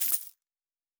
pgs/Assets/Audio/Fantasy Interface Sounds/Coins 07.wav
Coins 07.wav